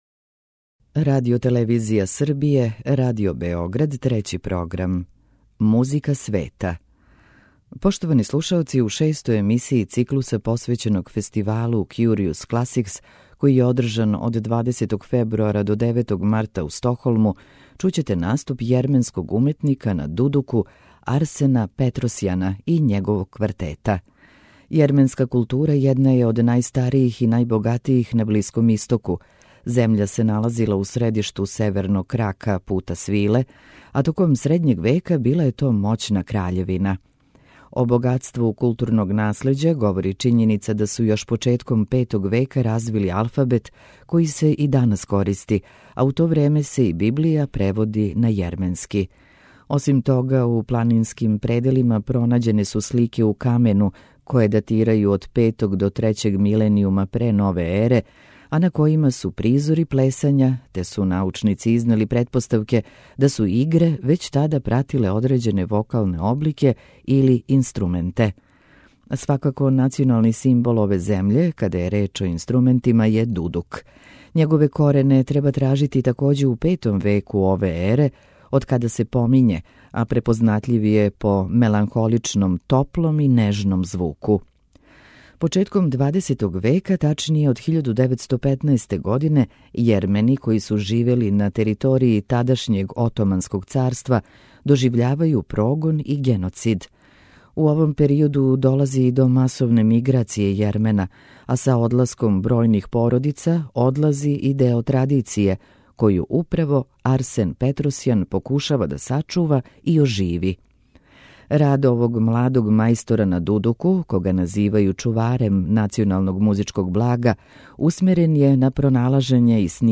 Snimak nastupa jermenskog umetnika na duduku
zabeležen 6. marta ove godine u dvorani Stalet u Stokholmu.